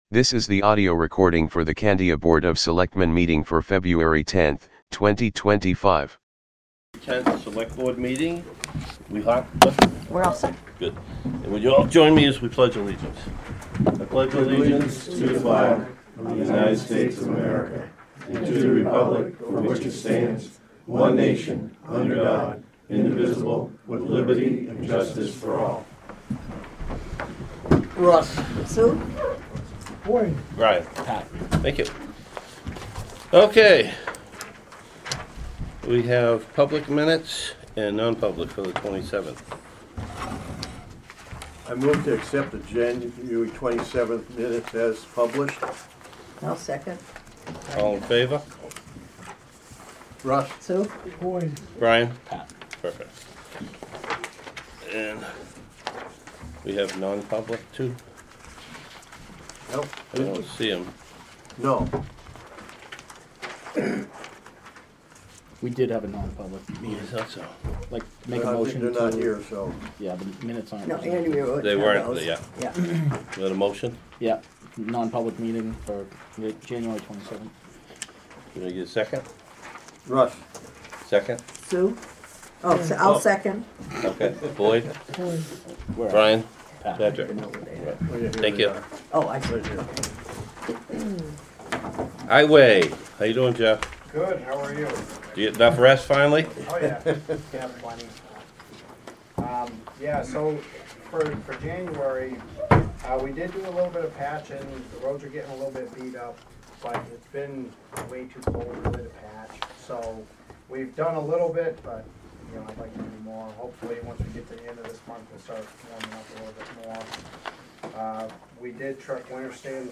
Audio recordings of committee and board meetings.
Board of Selectmen Meeting